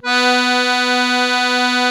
MUSETTE 1 .3.wav